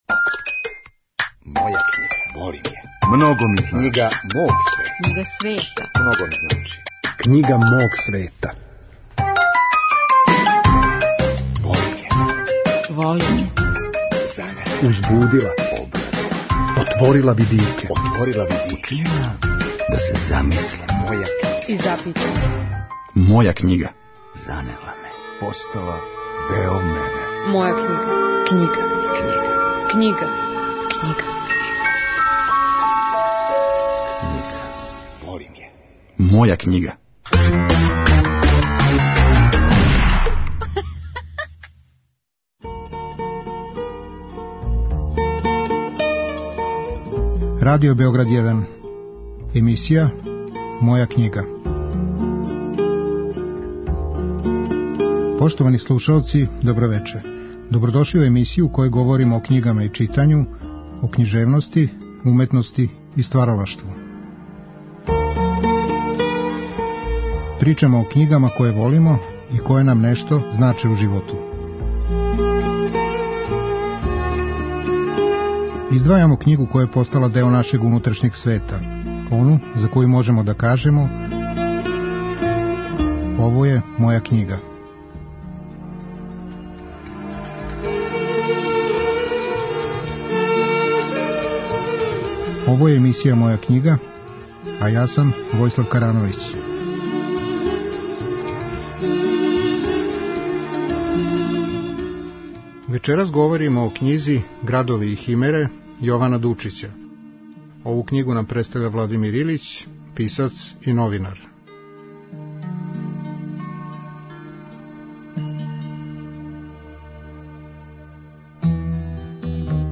Емисија о књигама и читању, о књижевности, уметности и стваралаштву. Гости су људи различитих интересовања, различитих занимања и професија.